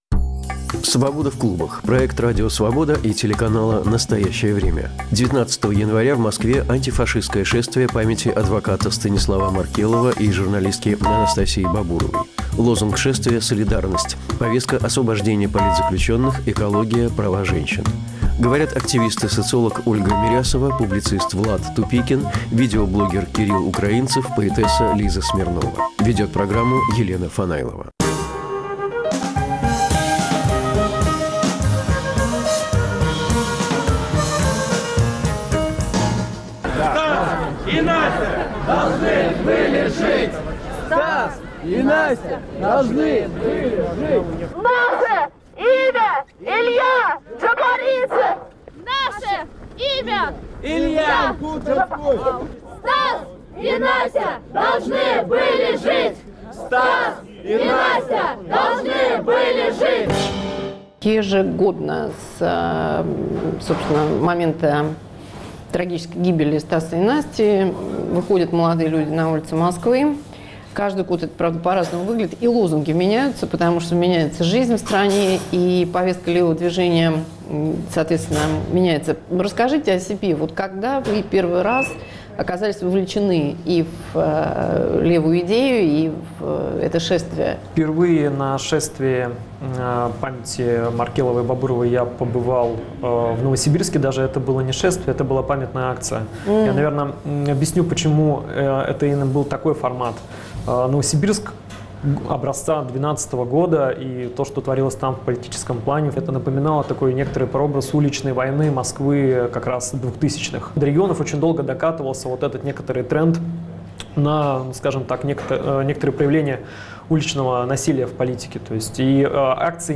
Антифашистское шествие в Москве 19 января: против политических репрессий, полицейской машины и беззакония. Говорят активисты политического протеста.